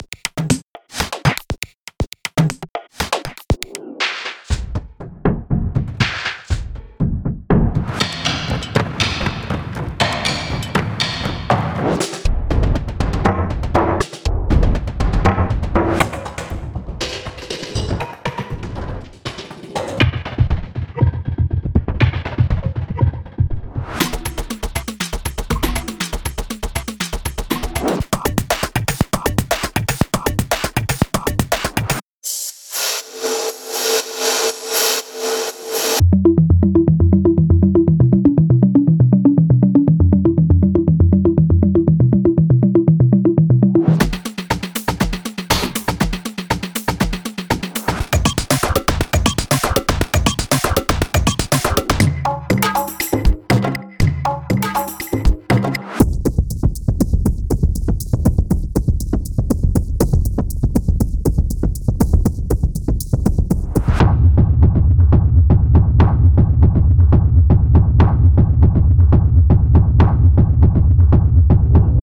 由于打击乐是如此强大，我们开发了一个体面的集合，包含抽象和实验性氛围的400多个单发和循环。
期望有新的实验性，独特的现代鼓和打击乐器循环集，以及由foley录音，扭曲的声学鼓，合成鼓机，调音打击乐器，颗粒合成以及在许多情况下的极端处理和效果制成的单音。
无论您制作何种流派，它涵盖了广泛的节奏（从80-172 BPM）和样式，该系列提供了新鲜的实验声音，可帮助您的下一首曲目脱颖而出。